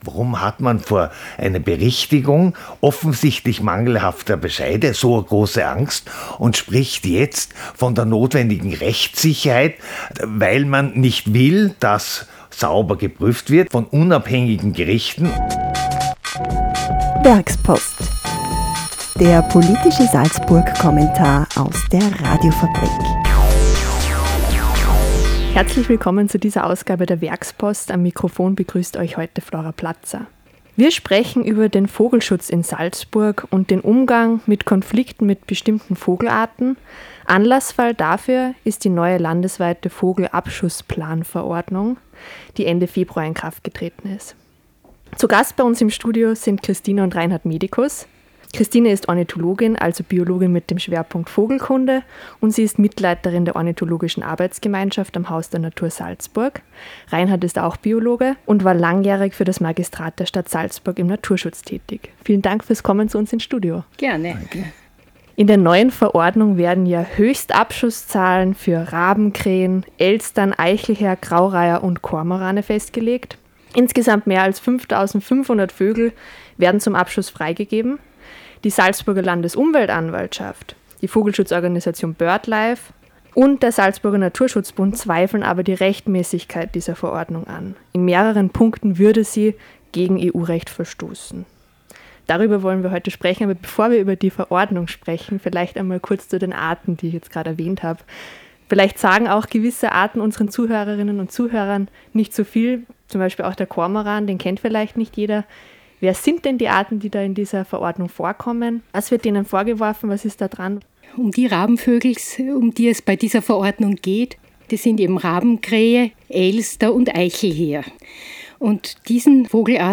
Der Werkspodcast vertieft das jeweilige Thema des Werkspost-Kommentars in einem Studiogespräch.